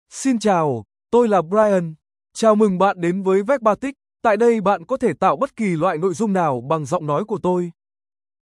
BrianMale Vietnamese AI voice
Brian is a male AI voice for Vietnamese (Vietnam).
Voice sample
Listen to Brian's male Vietnamese voice.
Brian delivers clear pronunciation with authentic Vietnam Vietnamese intonation, making your content sound professionally produced.